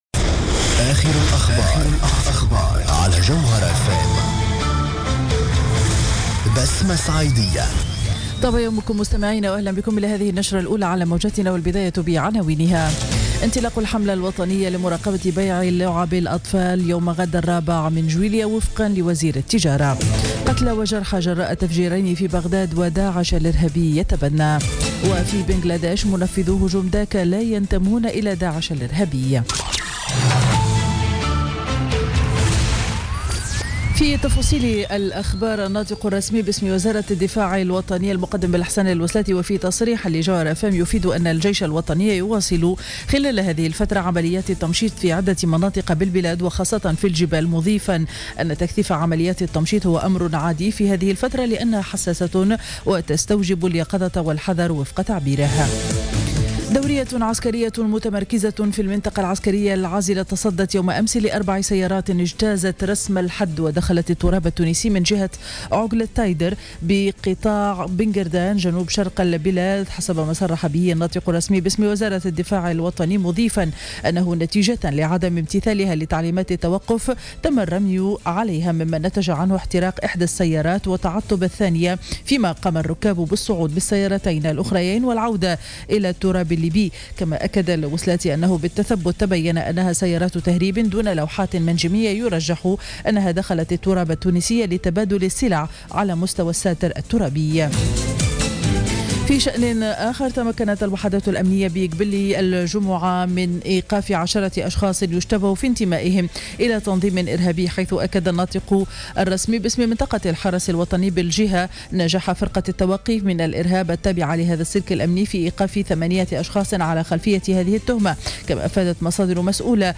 نشرة أخبار السابعة صباحا ليوم الأحد 3 جويلية 2016